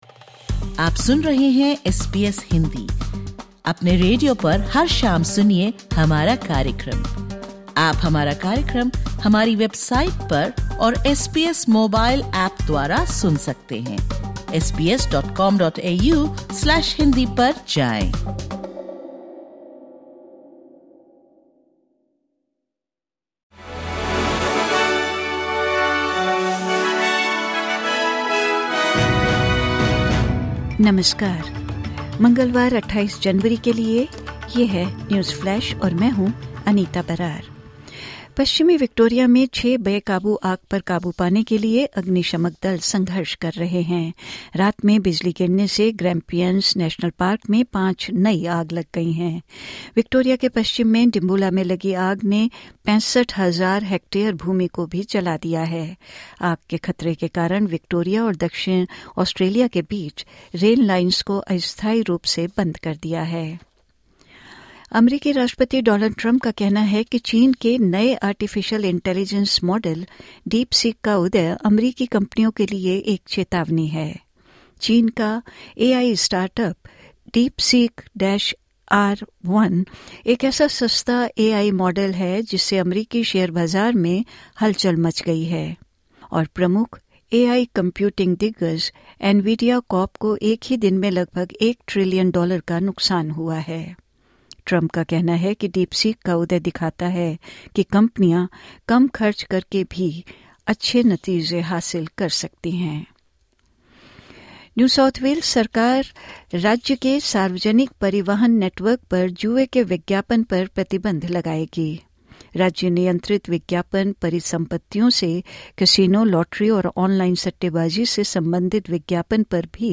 सुनें ऑस्ट्रेलिया और भारत से 28/01/2025 की प्रमुख खबरें।